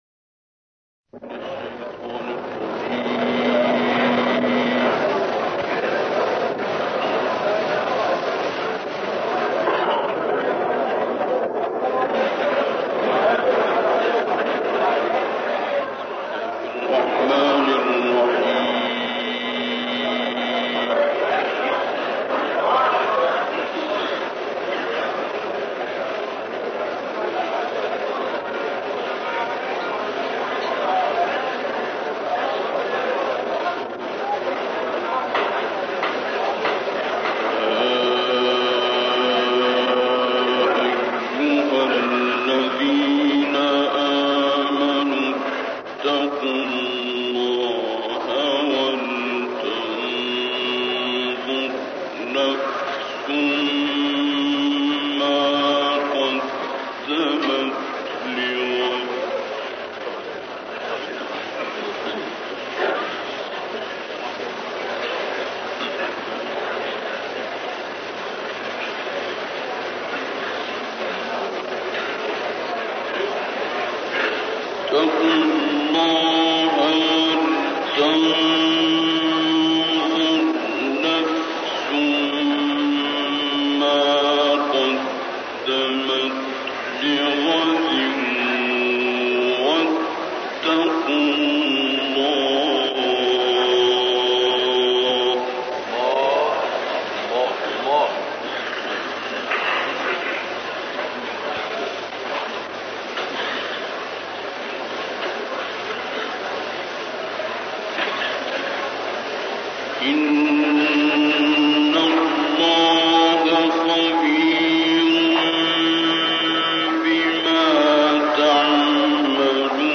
IQNA-इस्लामिक दुनिया में कुरान के सबसे यादगार पलों में से एक 1956 में इमाम काज़िम (AS) की पवित्र दरगाह पर अब्दुल बासित मुहम्मद अब्दुल समद की ऐतिहासिक तिलावत है।